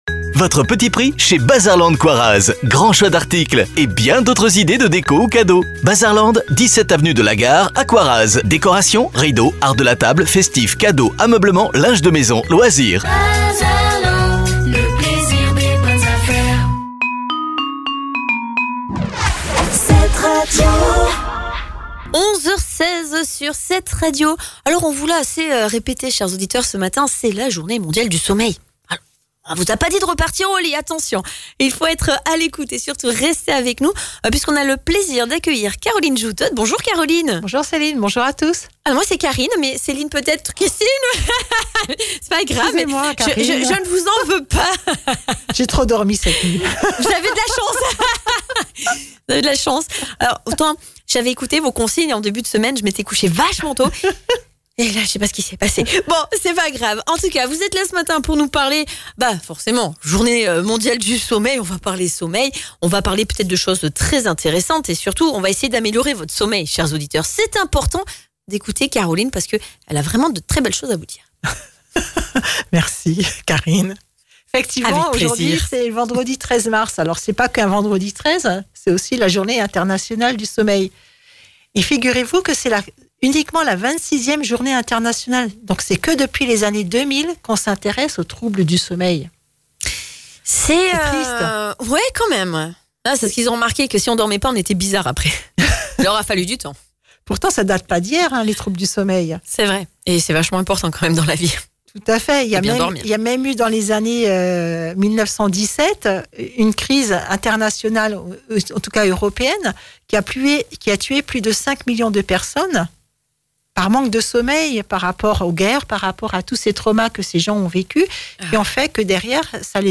Alors continuons d’en parler, comme nous l’avons fait à la radio Ne restez pas seul face à vos troubles du sommeil.